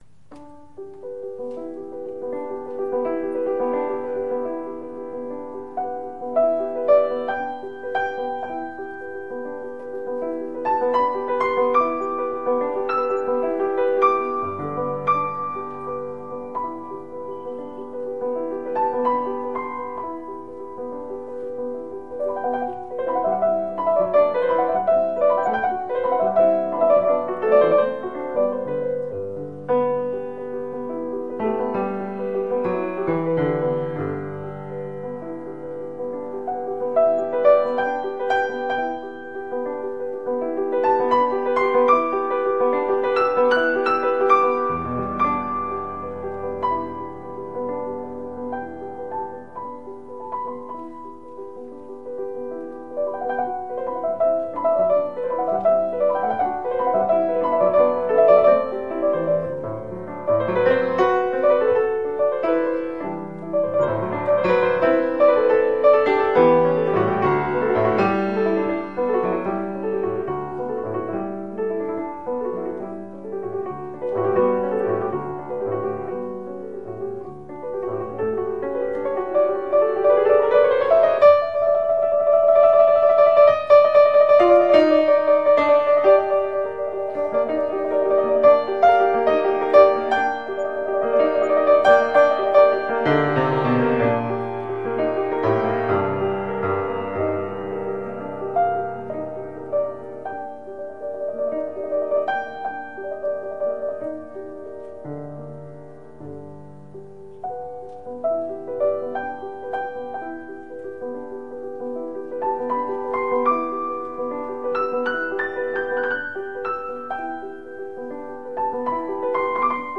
Récital de noël donné à la salle philharmonique de Ternopil en Ukraine.